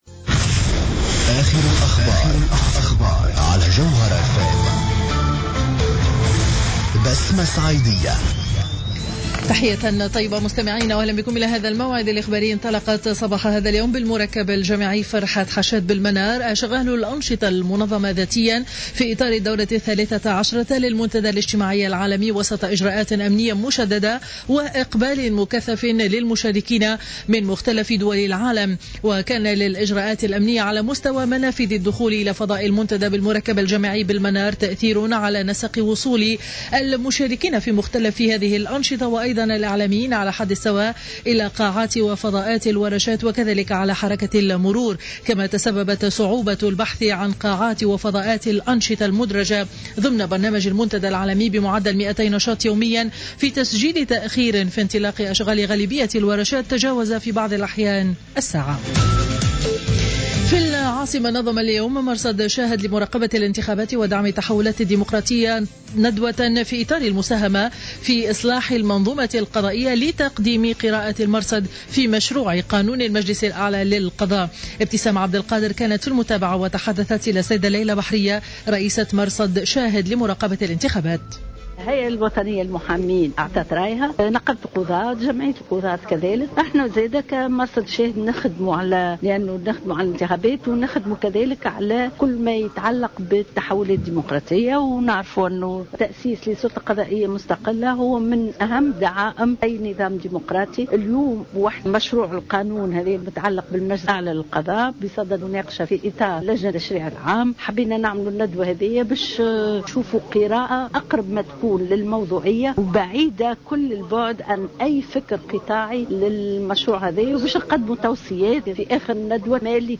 نشرة أخبار منتصف النهار ليوم الاربعاء 25 مارس 2015